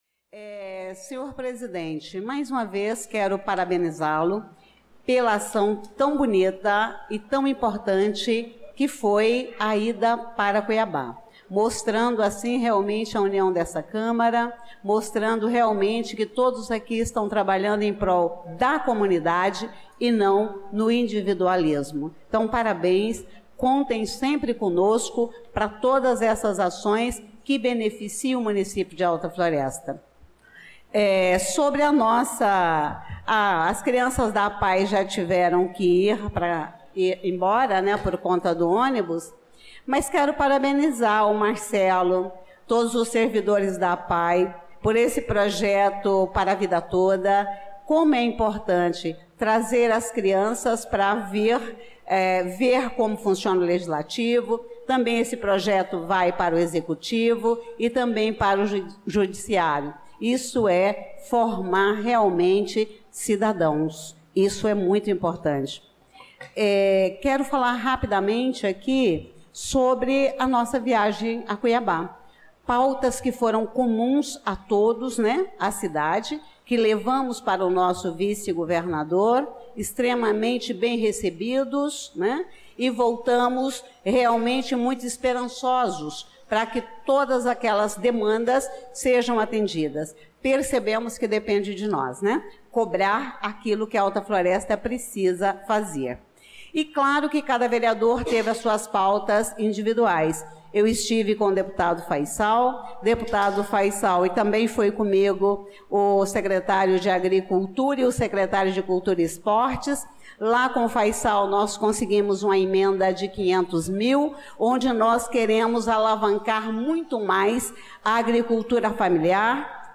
Pronunciamento da vereadora Elisa Gomes na Sessão Ordinária do dia 18/02/2025